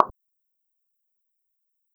menuclick.wav